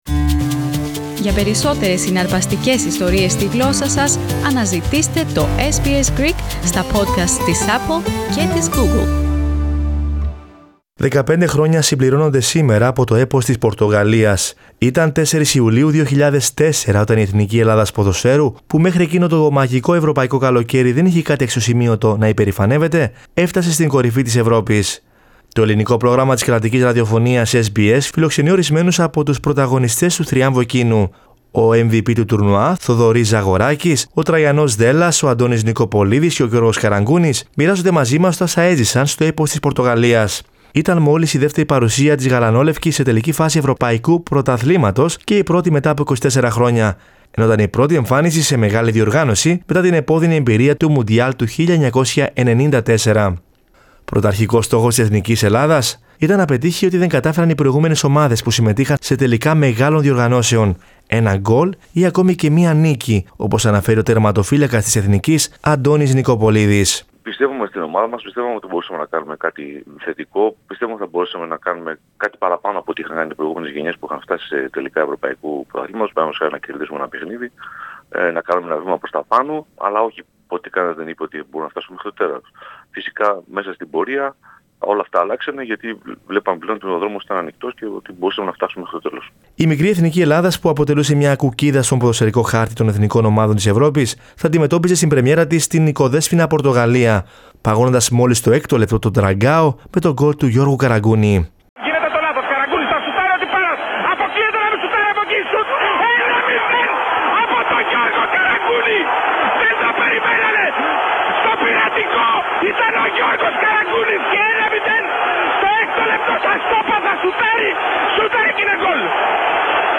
Θοδωρής Ζαγοράκης, Τραϊανός Δέλλας, Αντώνης Νικοπολίδης και Γιώργος Καραγκούνης, μοιράζονται μαζί μας τα όσα έζησαν στο έπος της Πορτογαλίας Source: Getty Images